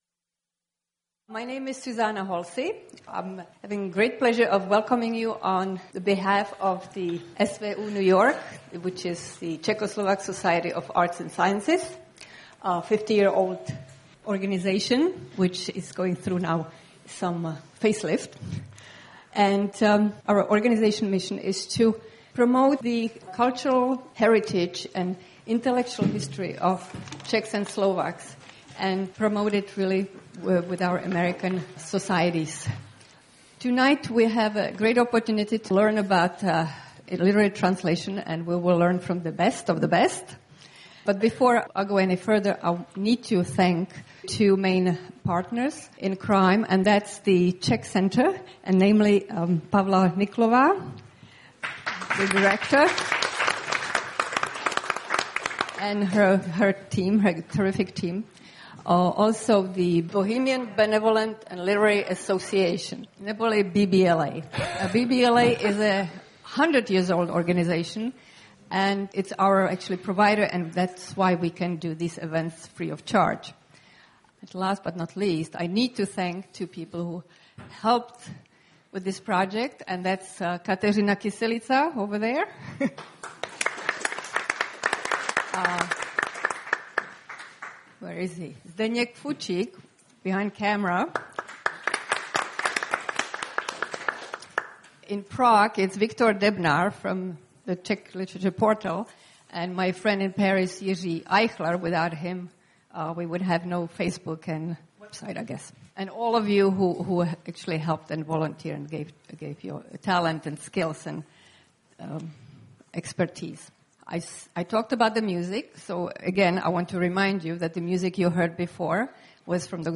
A panel discussion with renowned translators